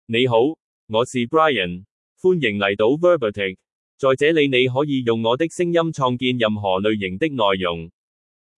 MaleChinese (Cantonese, Hong Kong)
Brian is a male AI voice for Chinese (Cantonese, Hong Kong).
Voice sample
Male
Brian delivers clear pronunciation with authentic Cantonese, Hong Kong Chinese intonation, making your content sound professionally produced.